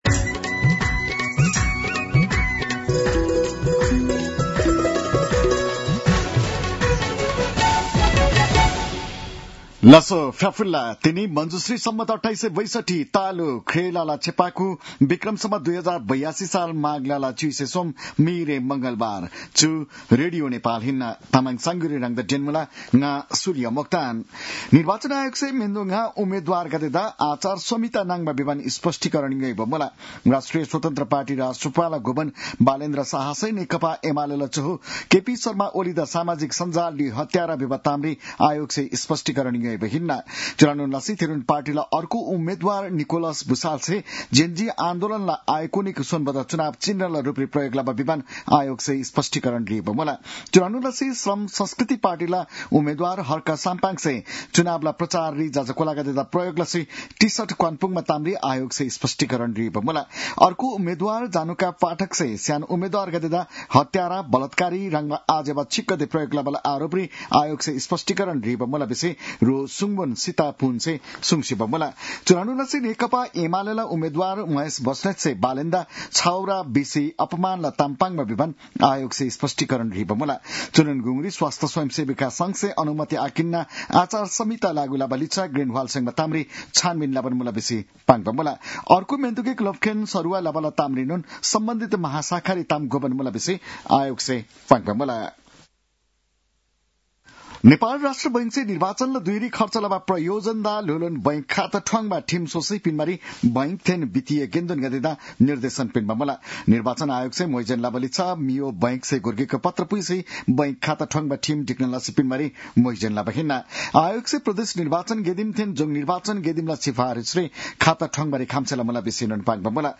तामाङ भाषाको समाचार : १३ माघ , २०८२